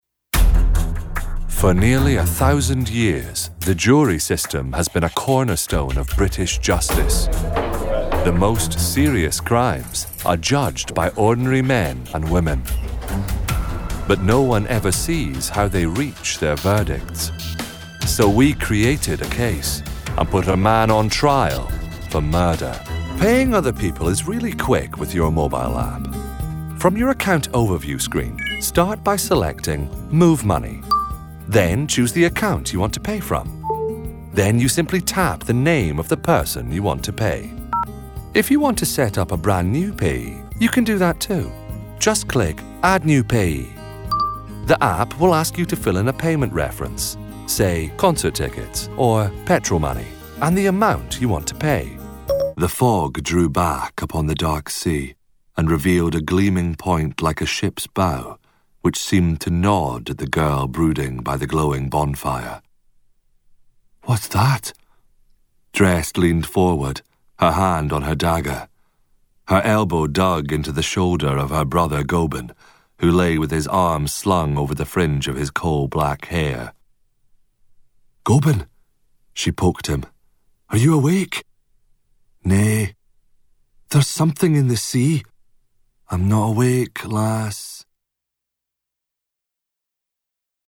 Audio Drama Showreel
He is proficient in UK and American accents, and his deep, resonant and clear voice has featured in over a hundred audiobooks to date.
Male
Relaxed